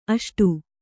♪ aṣṭu